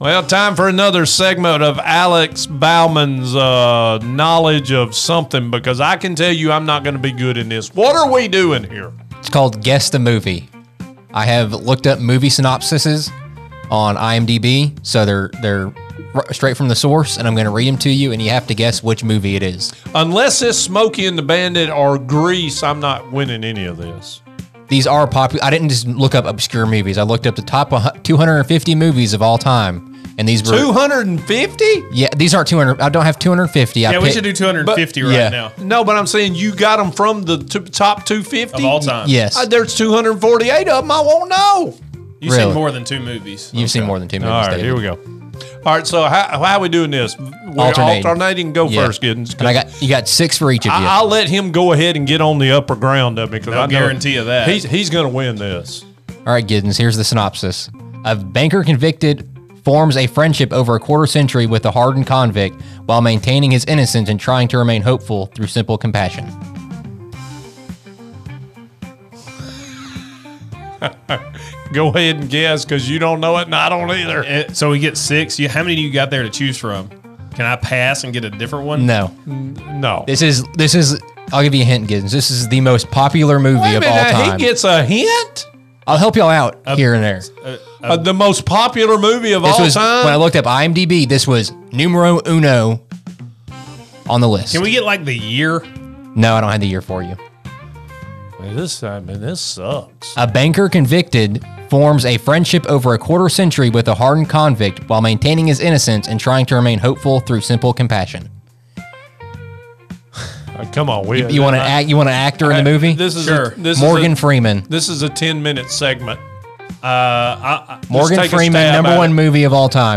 From classics to blockbusters, this episode is filled with movie knowledge, laughs, and plenty of wrong (and right) guesses.